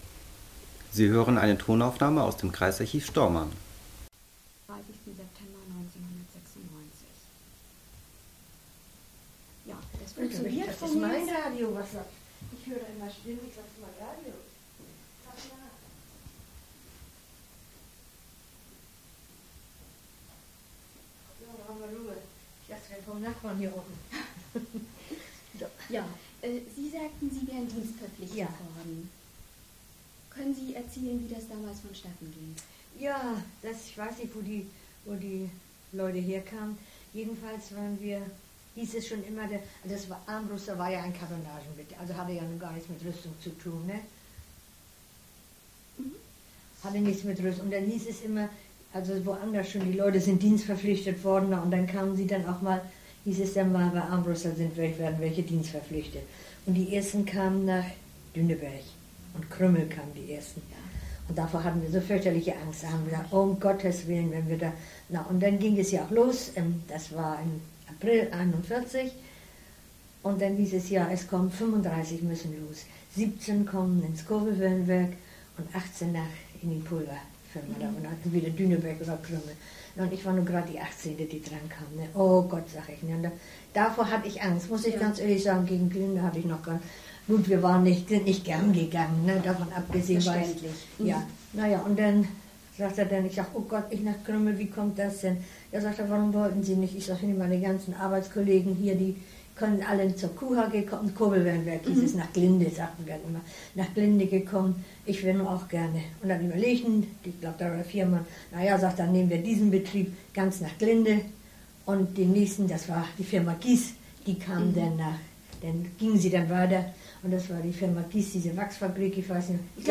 Tonkassette